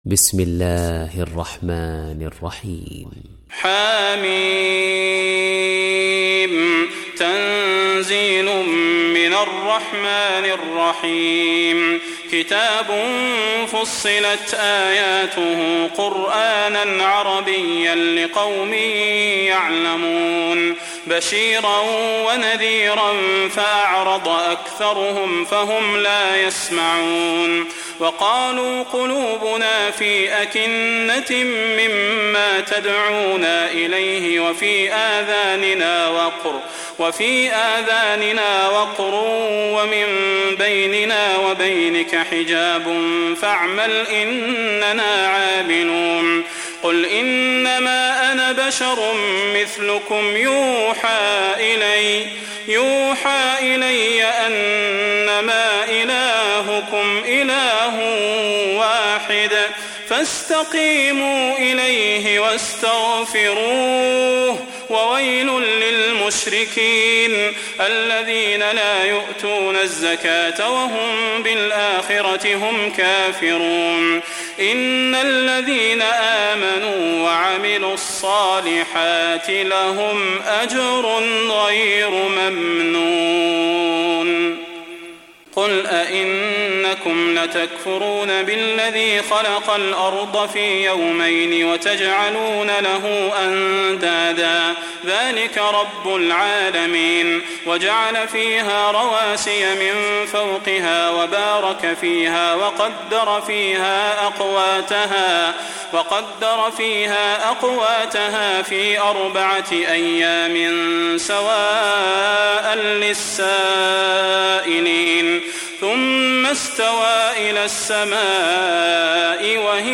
تحميل سورة فصلت mp3 بصوت صلاح البدير برواية حفص عن عاصم, تحميل استماع القرآن الكريم على الجوال mp3 كاملا بروابط مباشرة وسريعة